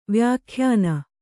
♪ vyākhyāna